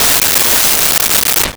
Window Shatter 01
Window Shatter 01.wav